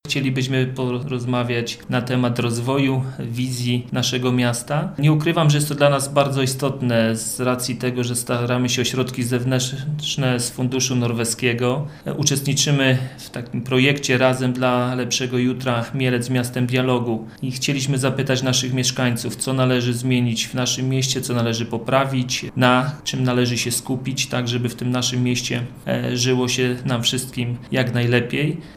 Mówi prezydent Mielca, Jacek Wiśniewski.